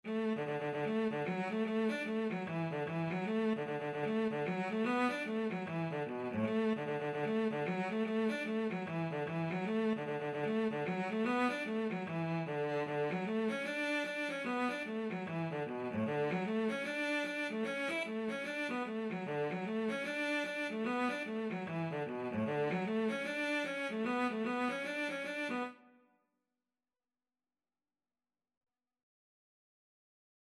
Traditional Music of unknown author.
D major (Sounding Pitch) (View more D major Music for Cello )
4/4 (View more 4/4 Music)
A3-E5
Cello  (View more Intermediate Cello Music)
Irish